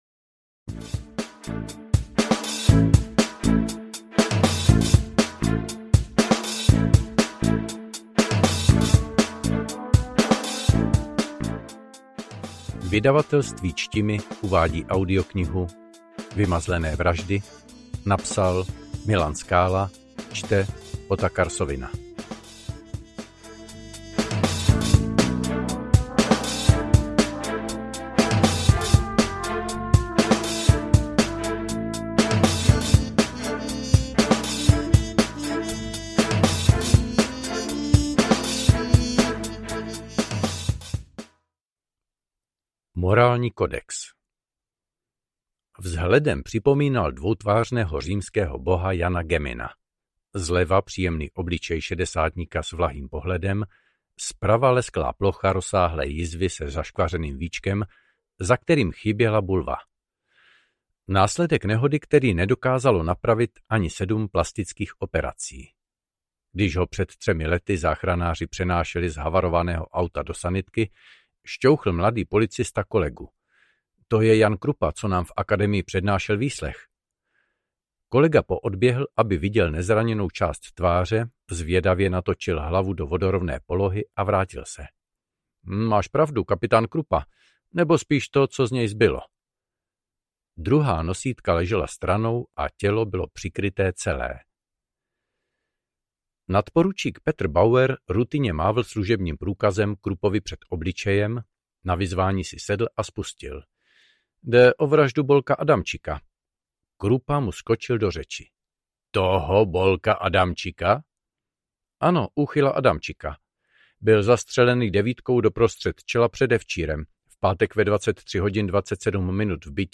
Interpret: Otakar Sovina
Kategorie: Detektivní, Dobrodružné, Povídkové